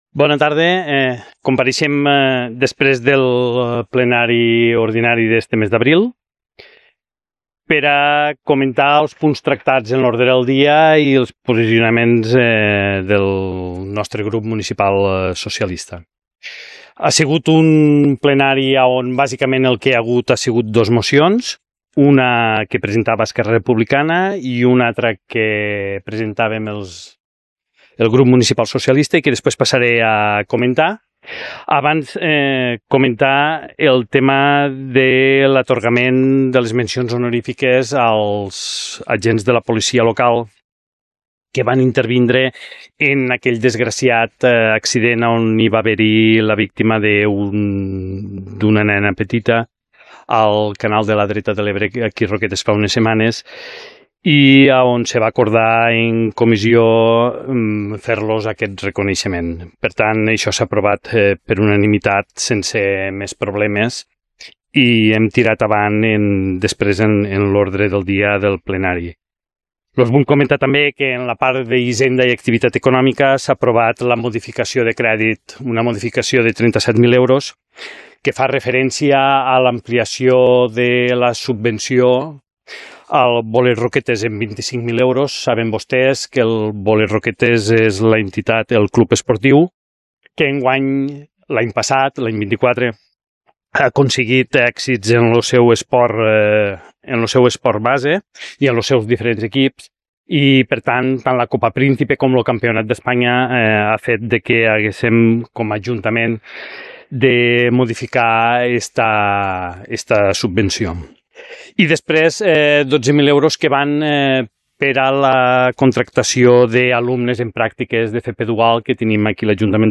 Ple Ordinari de Roquetes – Abril 2025 – Declaracions – PSC-CP – Josep Maria Beltrán | Antena Caro - Roquetes comunicació